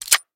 Circuit_Rotate.wav